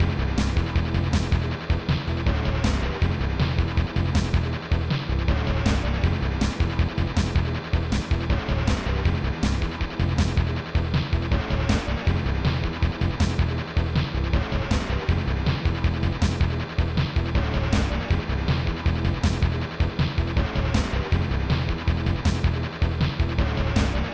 mod (ProTracker MOD (6CHN))
Fast Tracker 6CHN